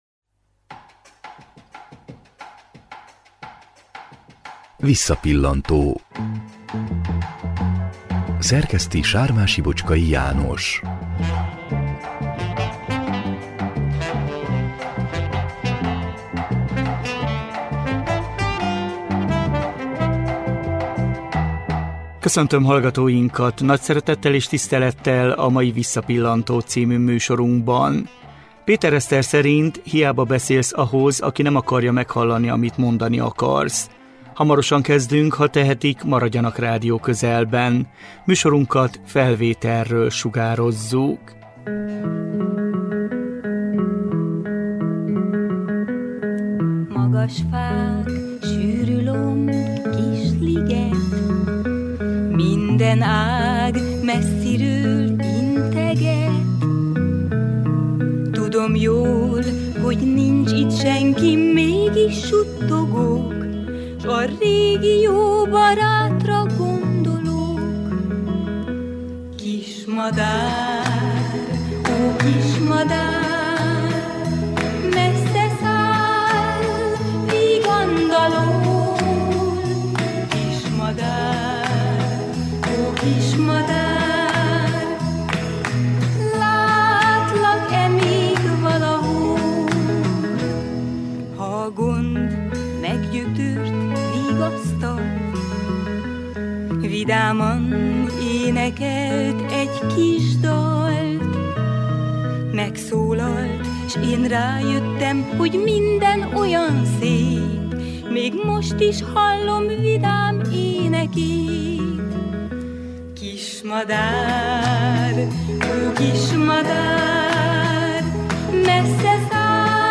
2009 márciusában beszélgettünk Vele.